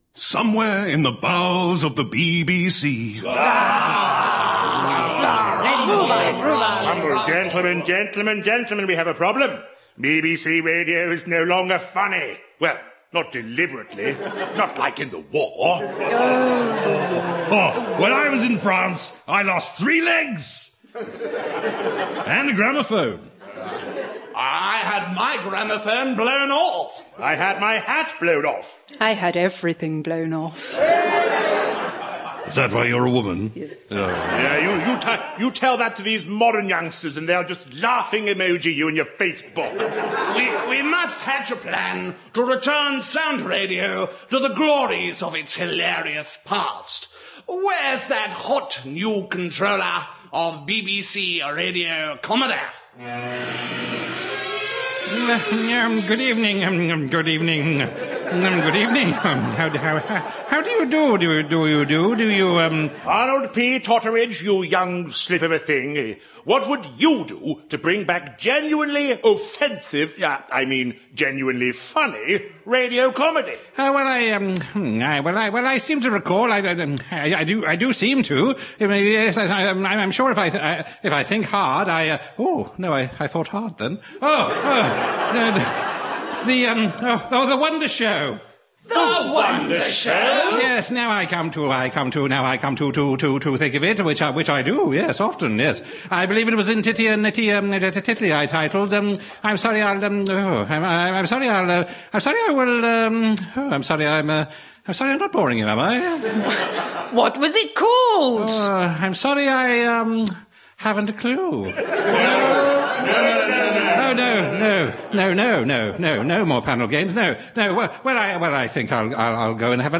An archive of the tribute radio comedy show I'm Sorry I'll Read That Again... Again!